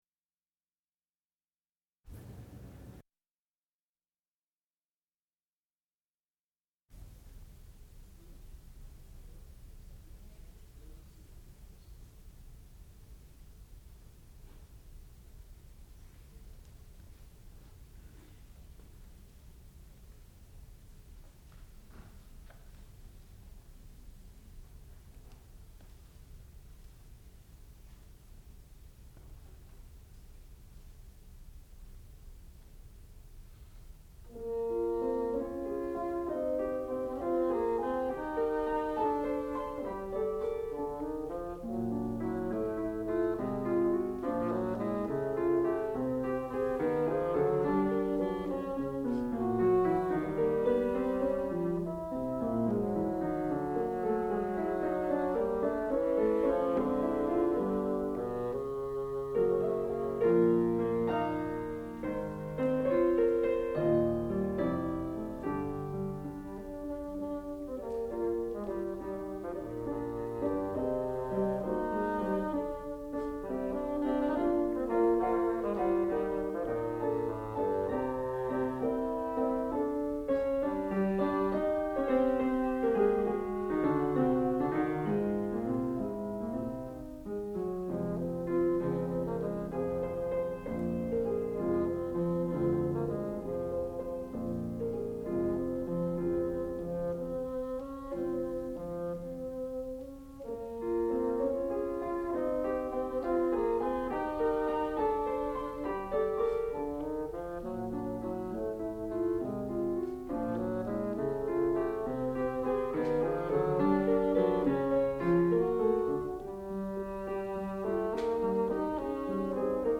Sonata for Bassoon and Piano
sound recording-musical
classical music
piano
bassoon